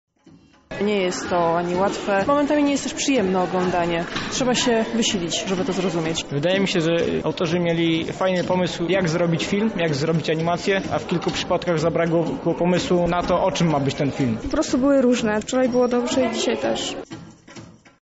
Co na temat wczorajszej projekcji sądzą widzowie?